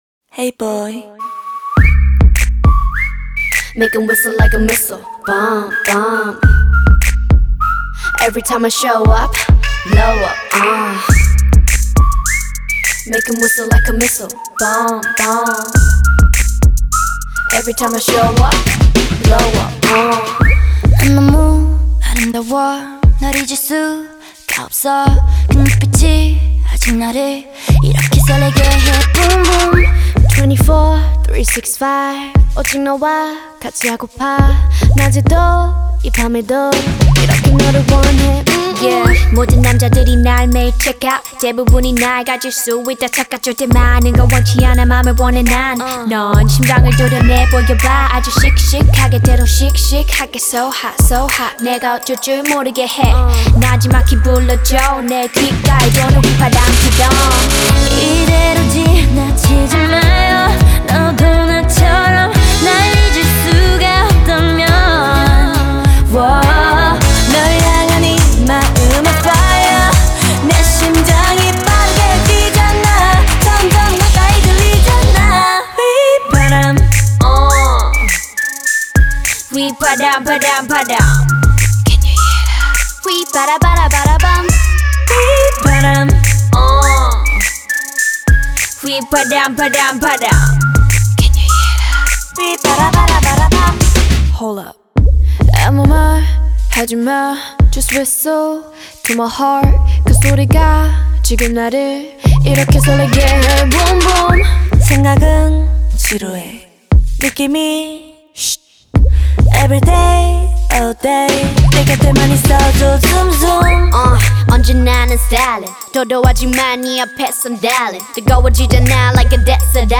South Korean girl group
”(Korean Version)